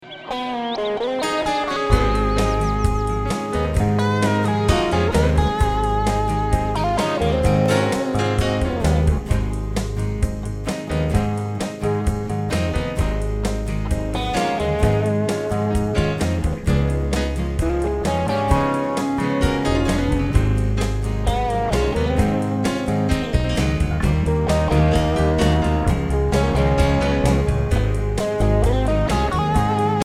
Listen to a sample of the instrumenal track.